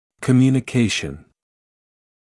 [kəˌmjuːnɪ’keɪʃn][кэˌмйуːни’кейшн]связь; коммуникация; общение, обмен информацией